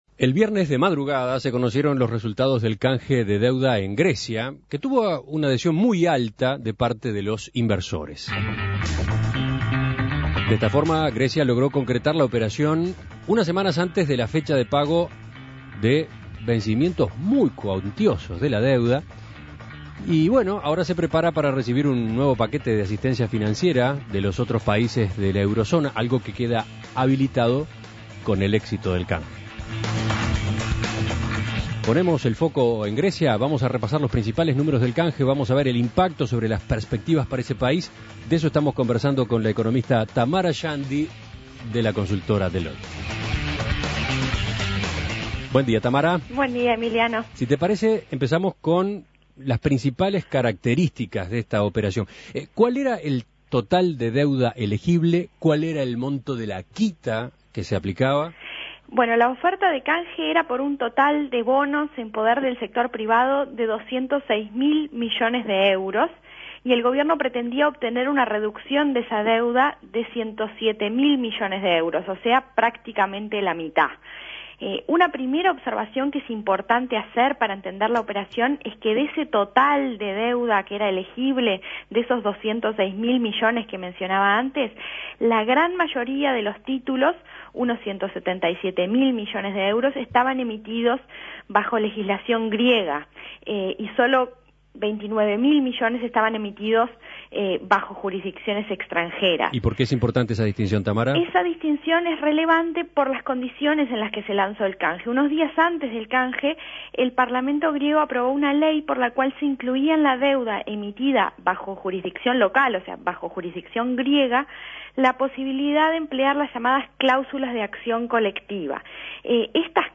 Análisis Económico ¿Cómo quedan las perspectivas para Grecia tras la operación de canje que se cerró la semana pasada?